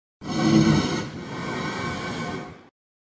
Звуки ифрита
На этой странице собраны звуки Ифрита — одного из самых опасных существ Нижнего мира в Minecraft. Здесь вы можете скачать или слушать онлайн его угрожающее рычание, звуки атак, возгорания и другие эффекты.